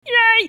Funny Yay - Sound Effect Button